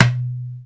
DUMBEK 4A.WAV